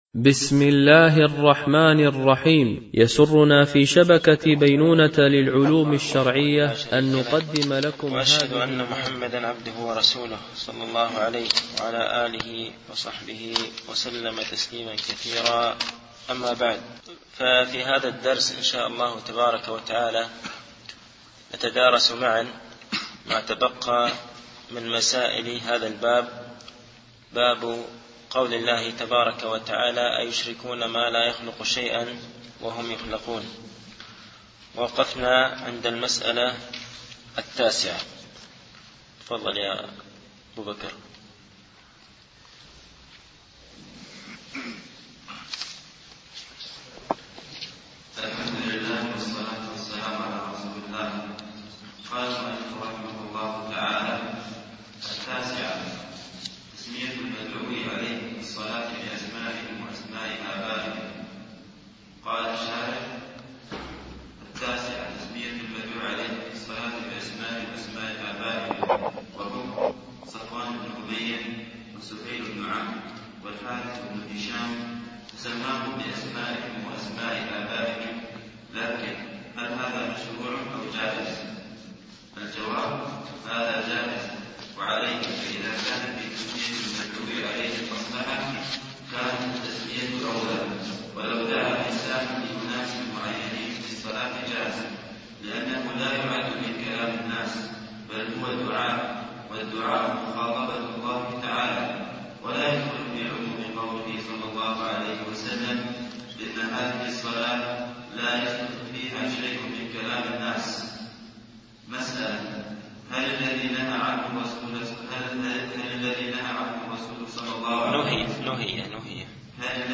الدرس الاربعون